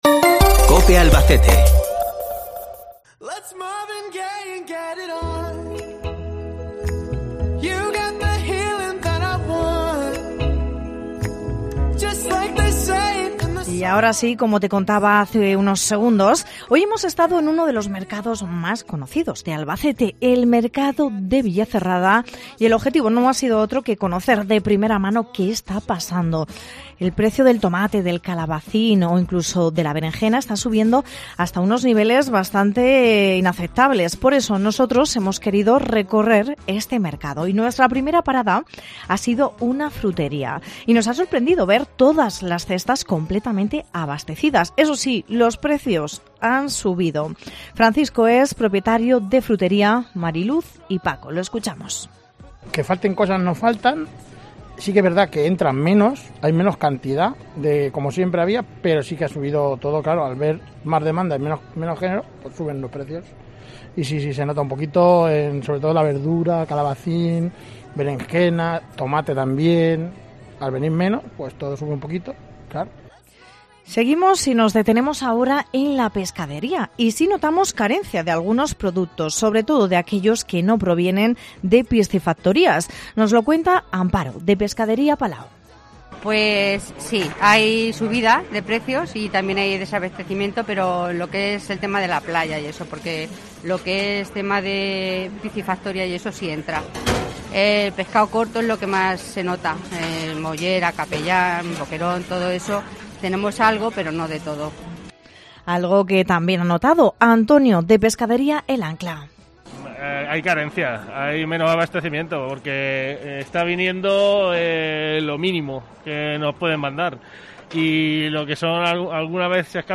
Audio reportaje mercado de villacerrada